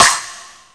INSNAREFX9-L.wav